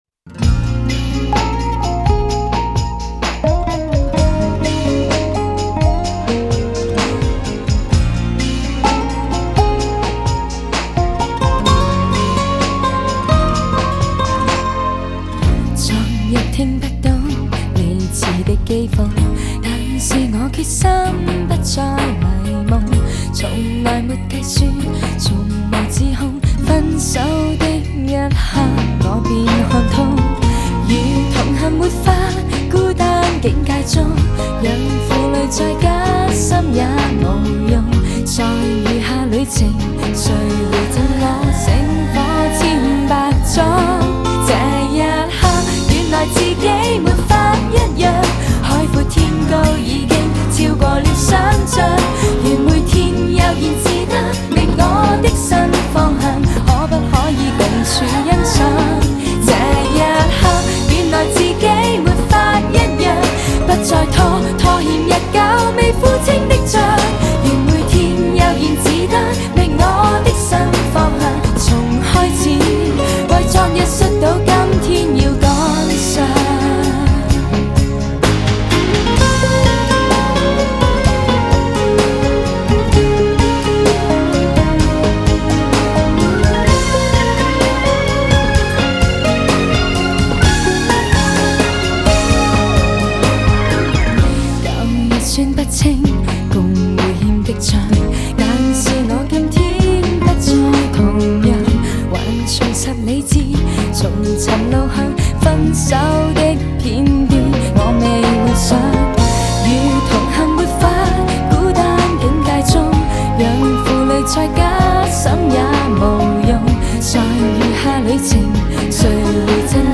类别: 国风